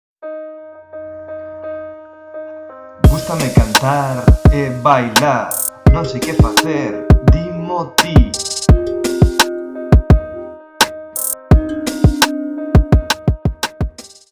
BASE MUSICAL CON LETRA